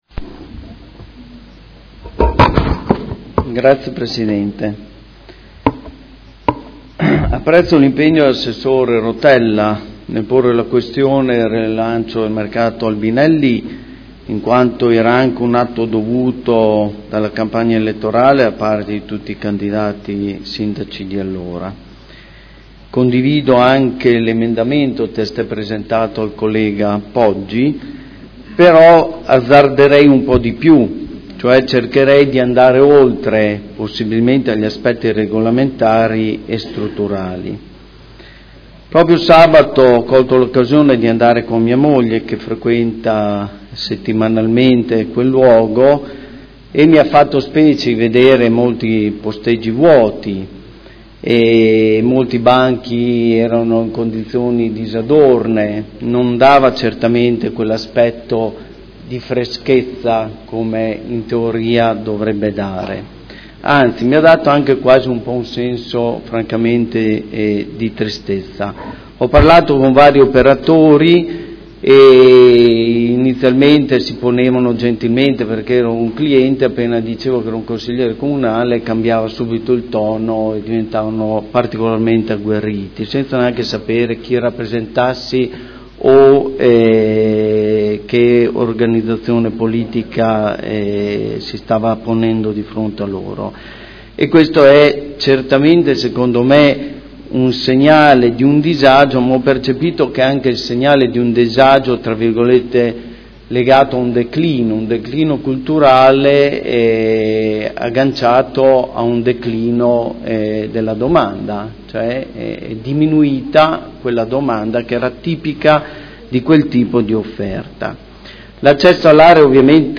Seduta del 3 marzo. Proposta di deliberazione: Regolamento Comunale del Mercato coperto quotidiano di generi alimentari denominato “Mercato Albinelli”, ai sensi dell’art. 27, primo comma, lettera D. del D.lgs 114/98 – Approvazione modifiche. Dibattito